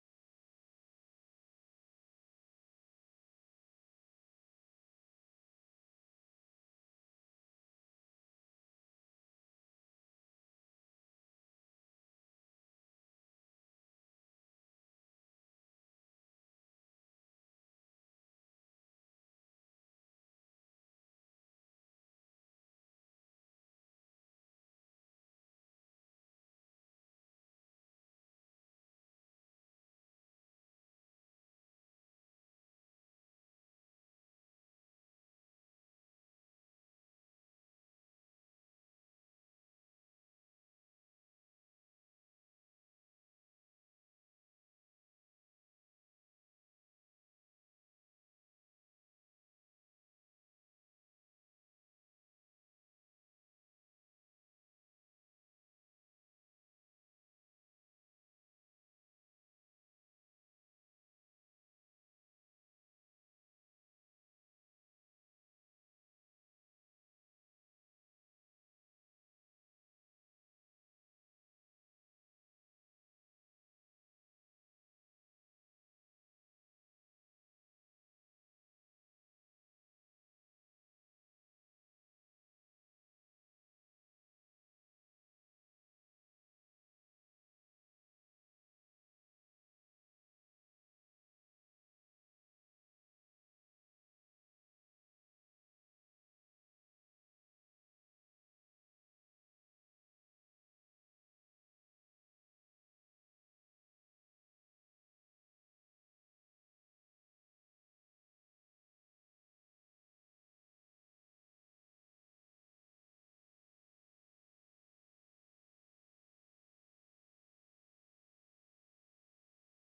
Programa transmitido el 11 de mayo del 2022.